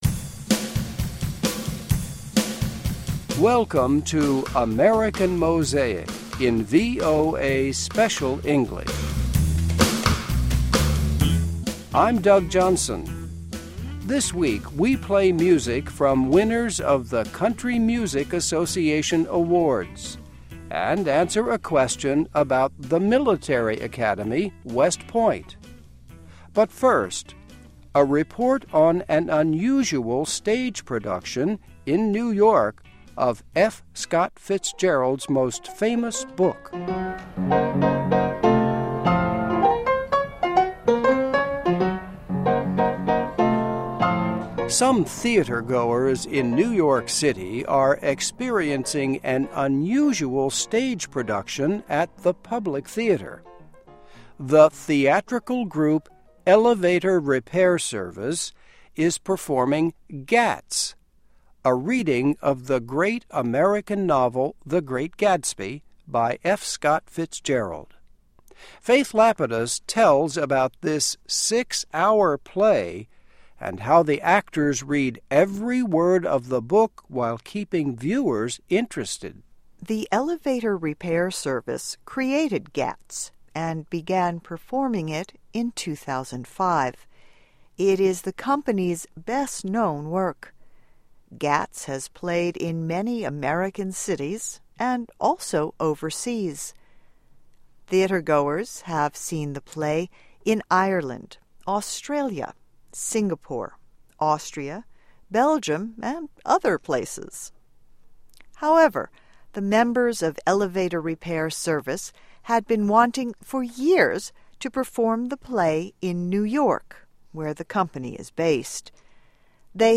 Listen and Read Along - Text with Audio - For ESL Students - For Learning English
This week we play music from winners of the Country Music Association awards ...